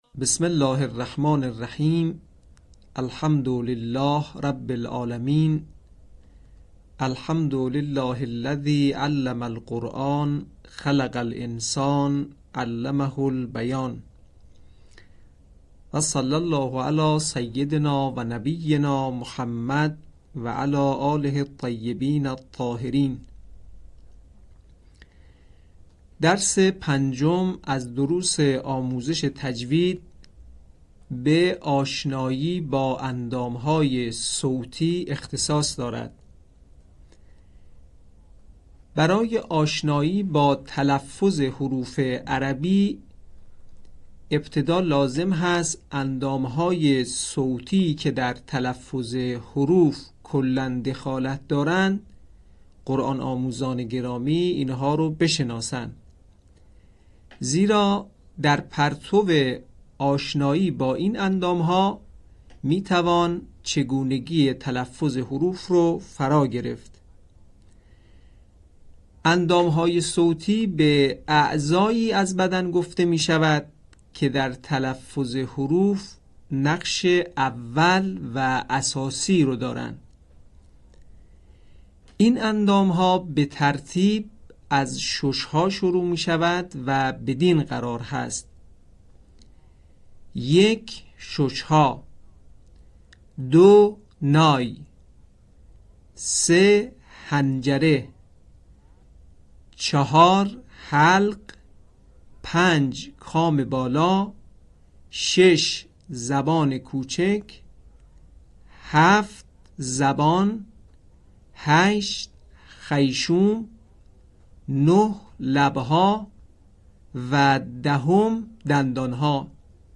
آموزش تجوید قرآن